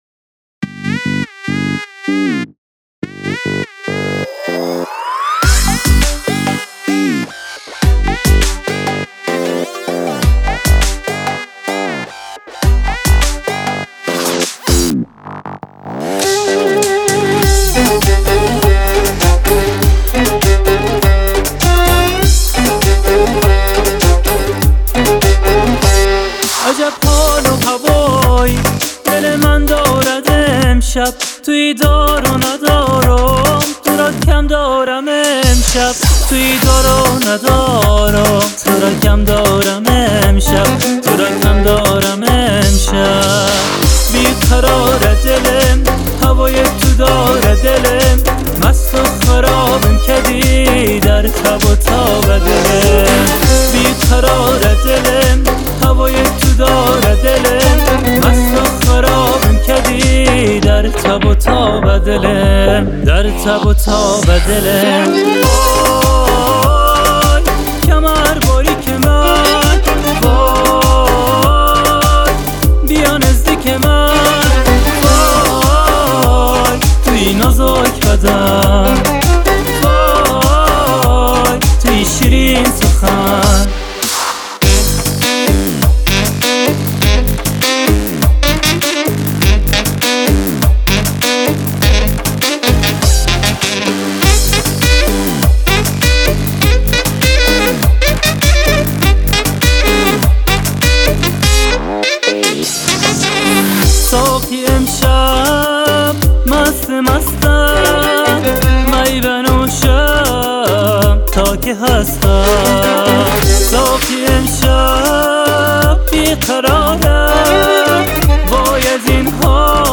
آهنگ افغانی